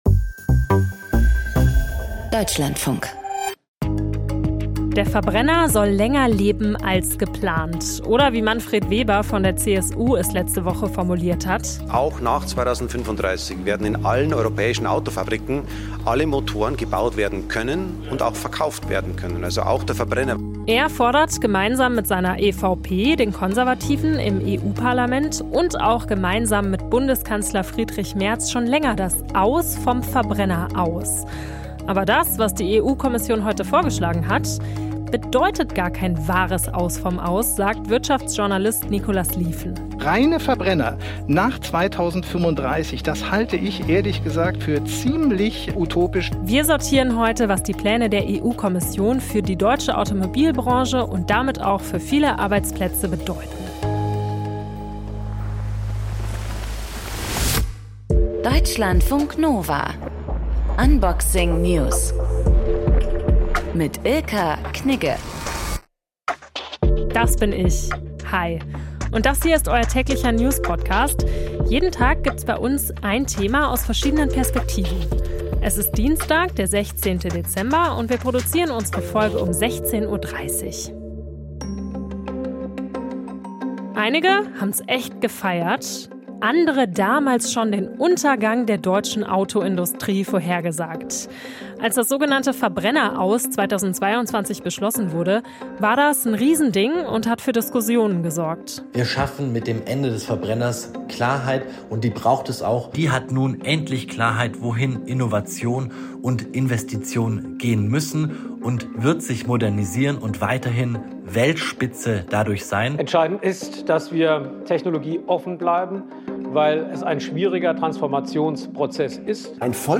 Wirtschaftsjournalist